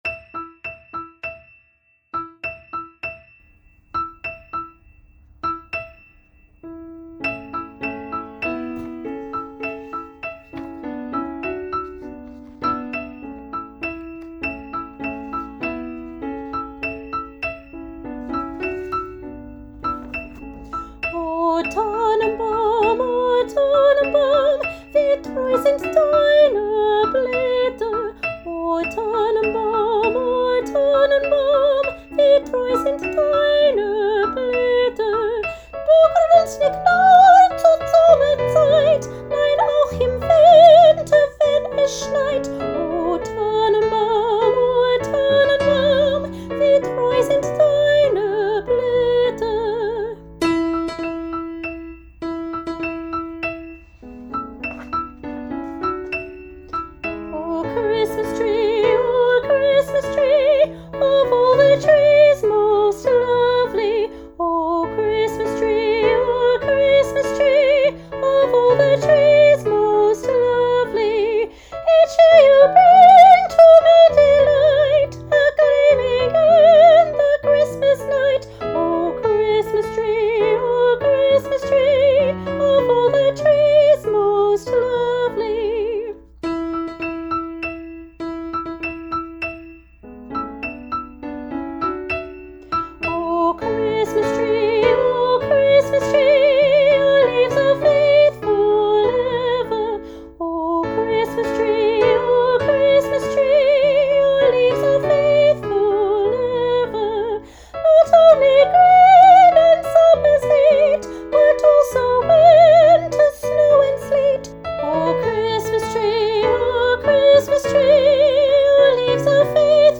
Junior Choir – O Tannenbaum, Combined Piece, Top Line – Soprano